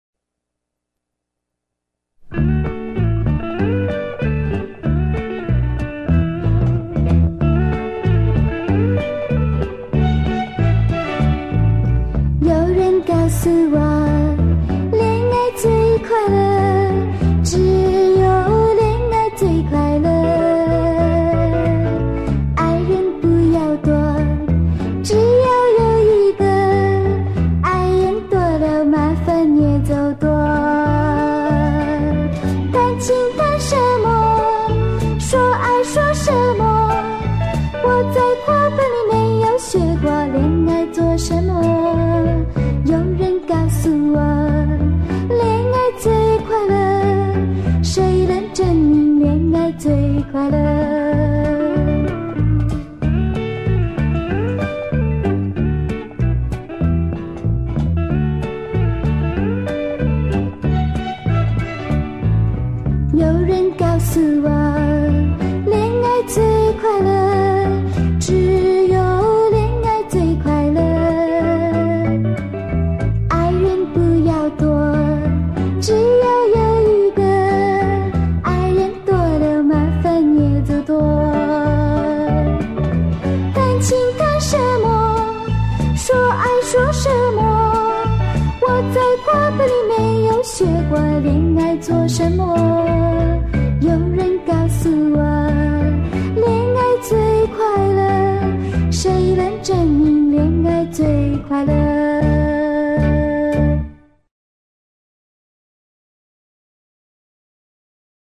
注意：黑膠轉錄，單聲道，不喜勿下！